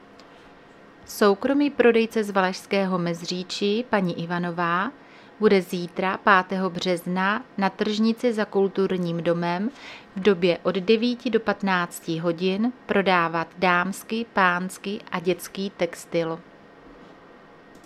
Záznam hlášení místního rozhlasu 4.3.2026
Zařazení: Rozhlas